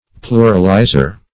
Pluralizer \Plu"ral*i`zer\